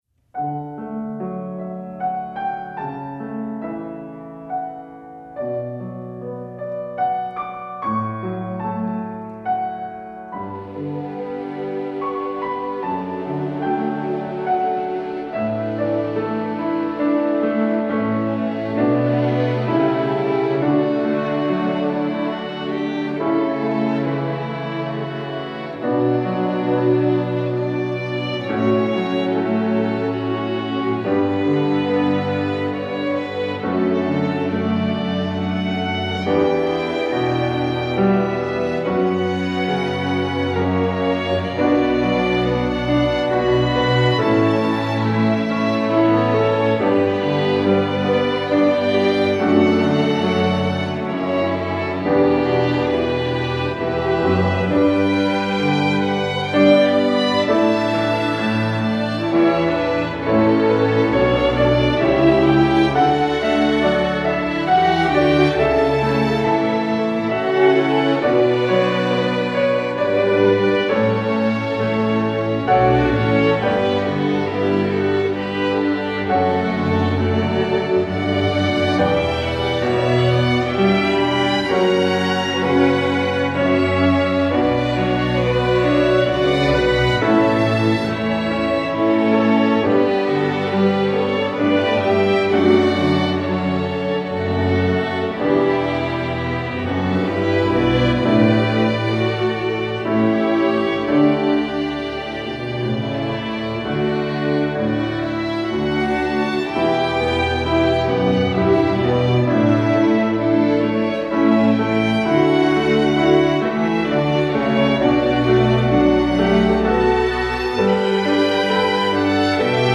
Instrumentation: string orchestra (full score)